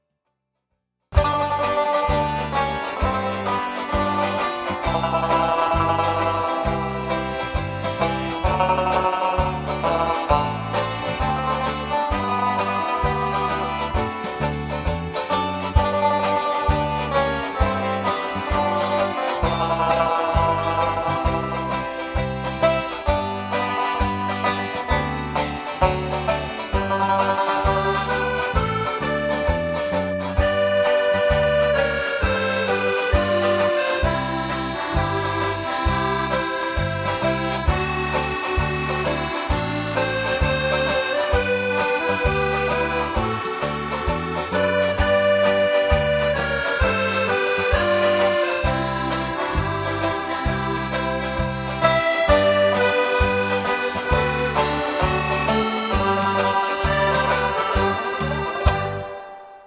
8-beat intro.